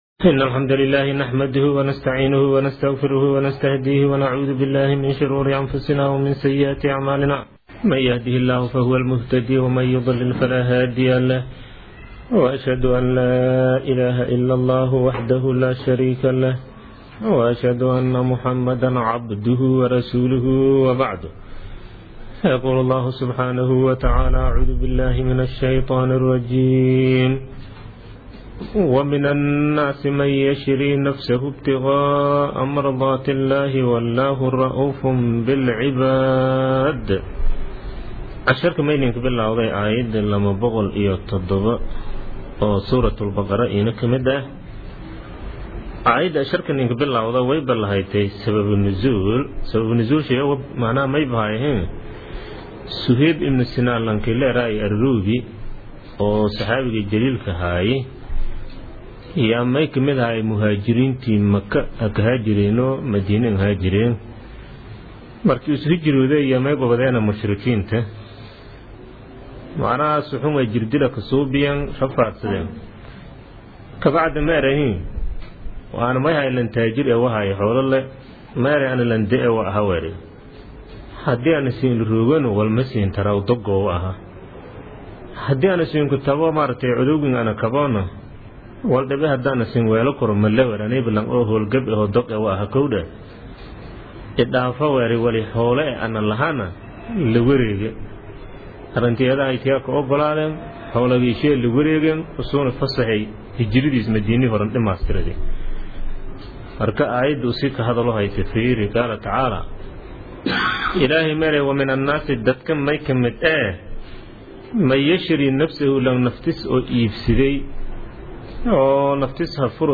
Casharka Tafsiirka Maay 26aad